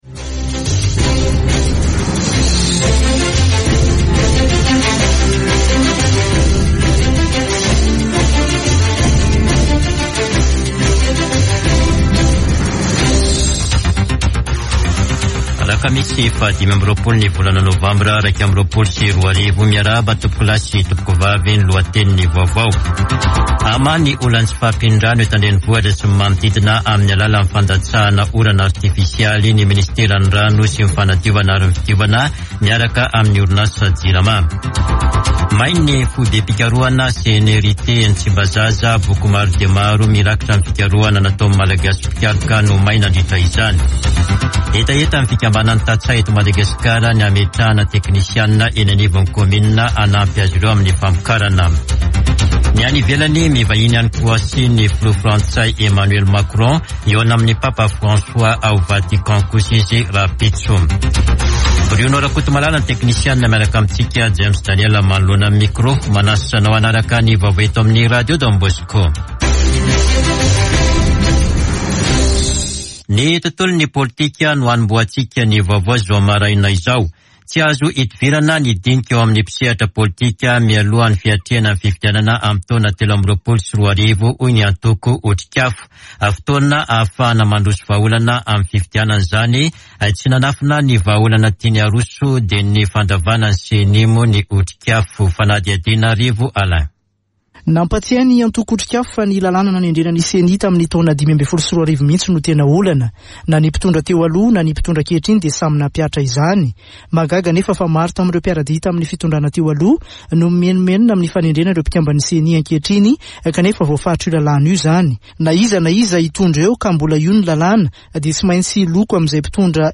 [Vaovao maraina] Alakamisy 25 novambra 2021